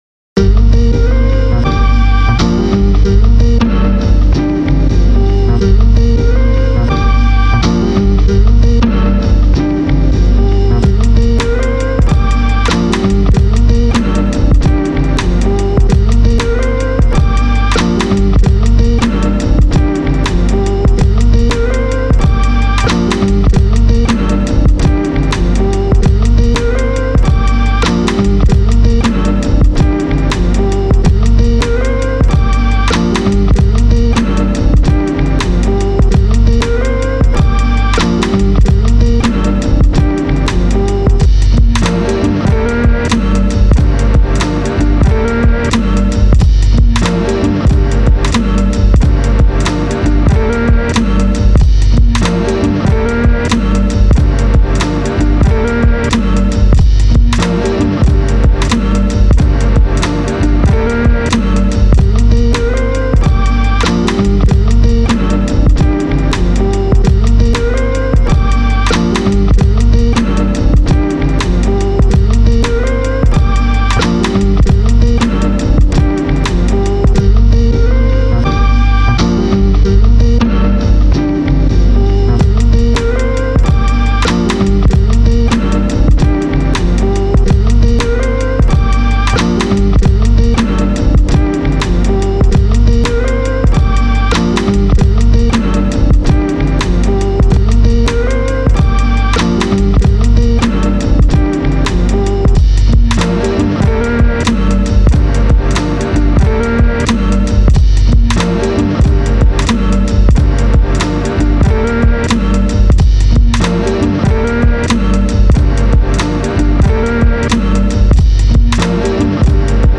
Super slop on the 404.
Listened first time on earphones today and the bass is way too much :grimacing: